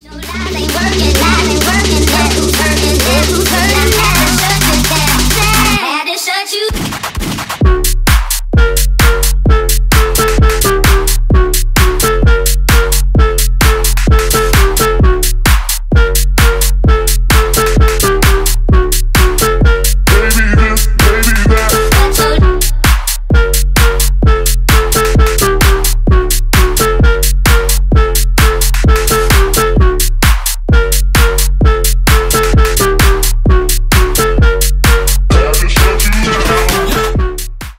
Электроника
клубные # громкие